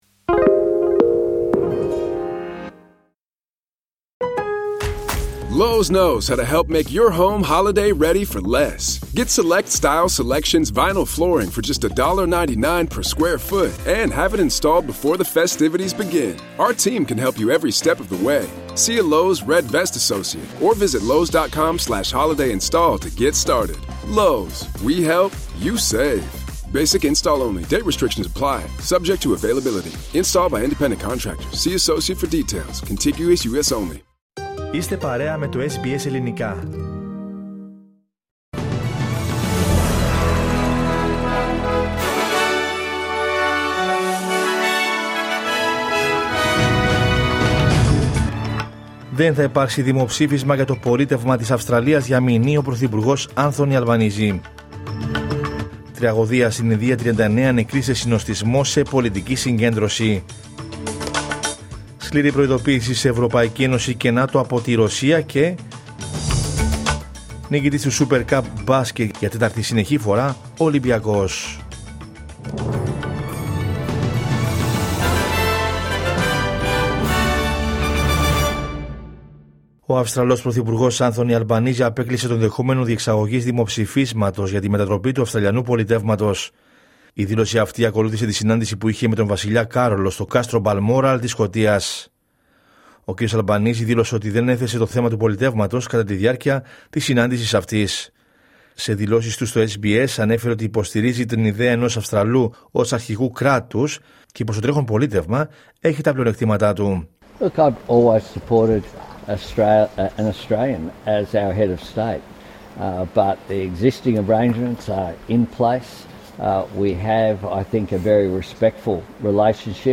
Ειδήσεις από την Αυστραλία, την Ελλάδα, την Κύπρο και τον κόσμο στο Δελτίο Ειδήσεων της Κυριακής 28 Σεπτεμβρίου 2025.